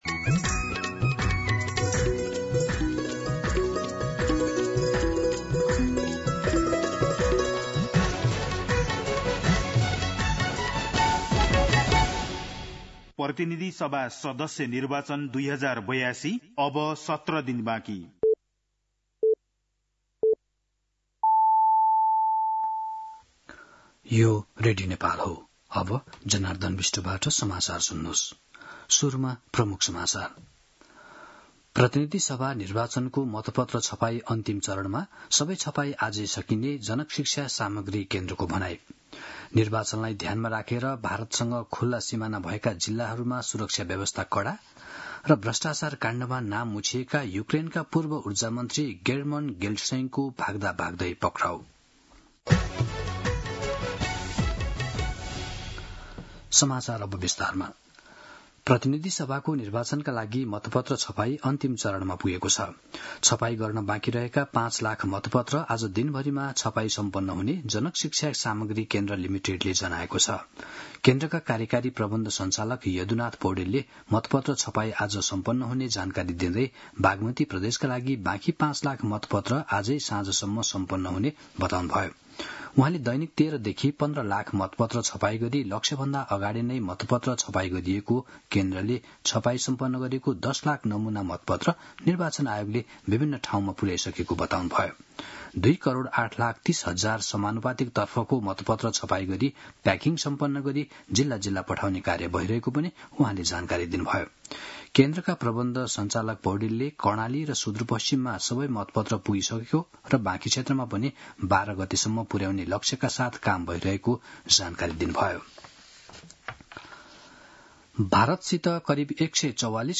दिउँसो ३ बजेको नेपाली समाचार : ४ फागुन , २०८२